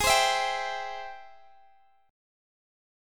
Ab+M7 chord